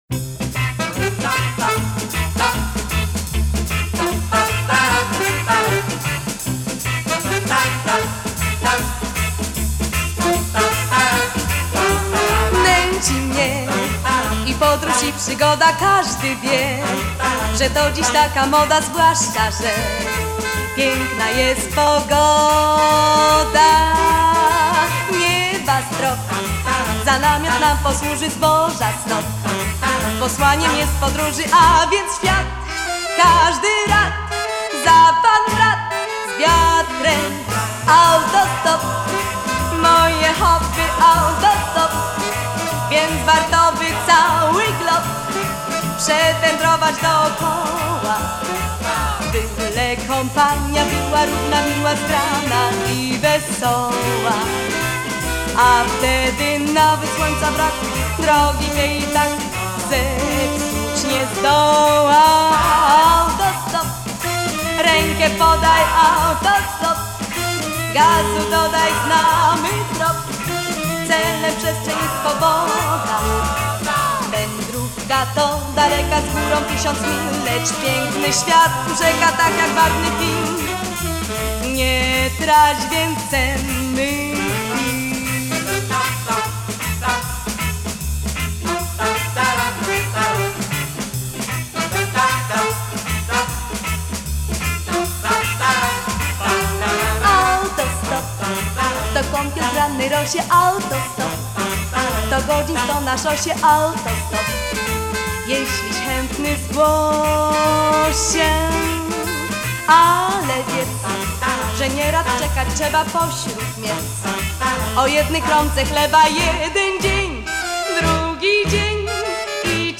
Genre: Retro, Twist, 60s, Female Vocal